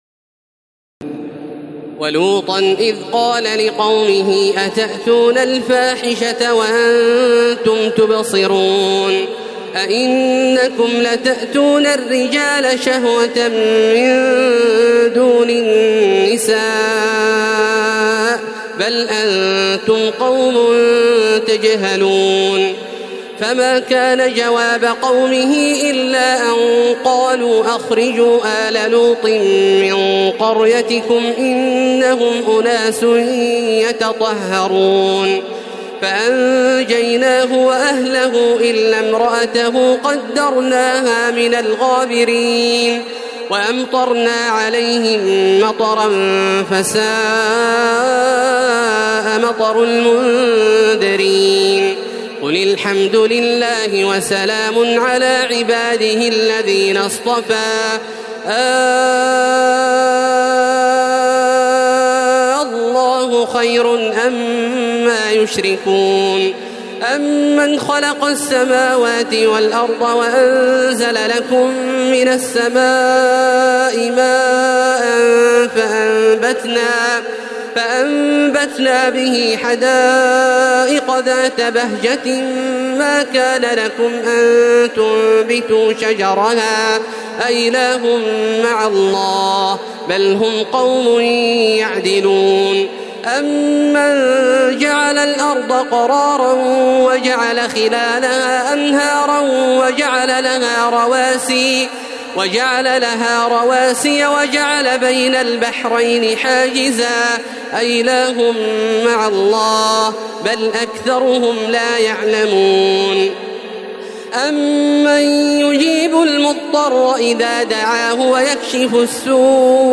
تراويح الليلة التاسعة عشر رمضان 1432هـ من سورتي النمل(54-93) و القصص(1-50) Taraweeh 19 st night Ramadan 1432H from Surah An-Naml and Al-Qasas > تراويح الحرم المكي عام 1432 🕋 > التراويح - تلاوات الحرمين